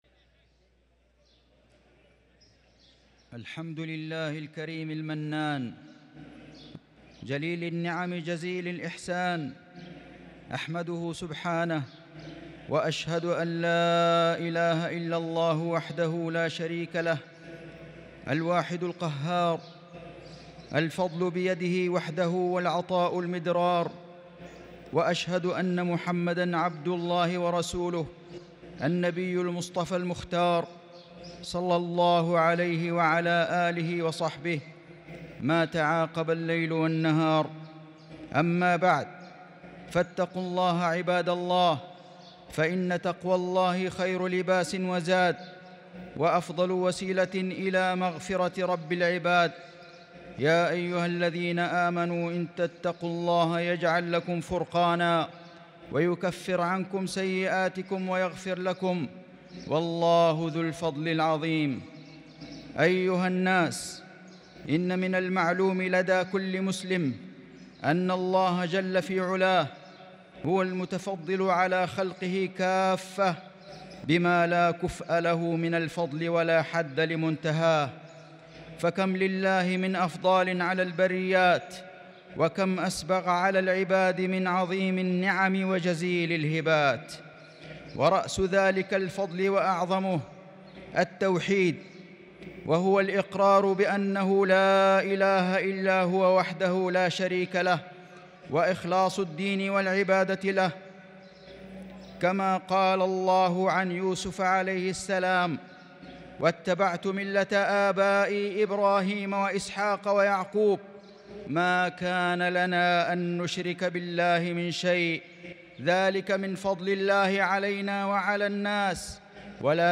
خطبة الجمعة ٢١ رمضان ١٤٤٣هـ > خطب الحرم المكي عام 1443 🕋 > خطب الحرم المكي 🕋 > المزيد - تلاوات الحرمين